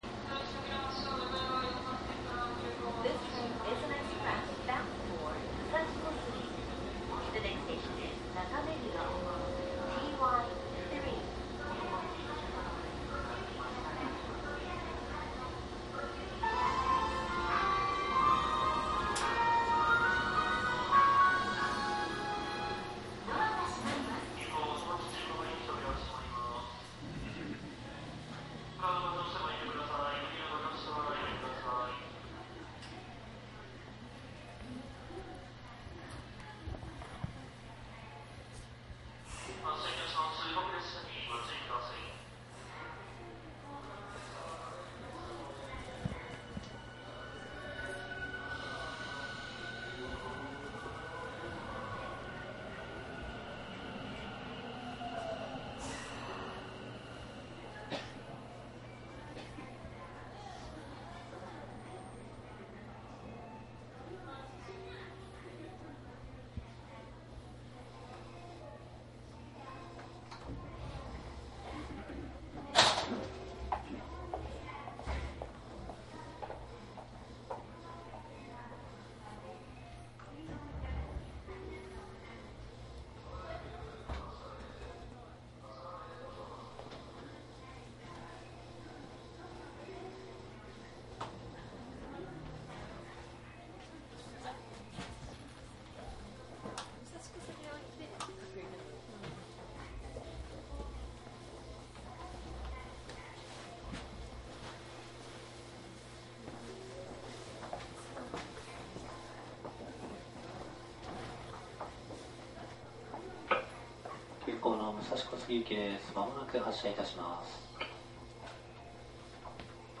東武 50070系  副都心線 走行音 CD
東武50000系で70番台の副都心線暫定開業時の録音。
いずれもマイクECM959です。TCD100の通常SPモードで録音。
実際に乗客が居る車内で録音しています。貸切ではありませんので乗客の会話やが全くないわけではありません。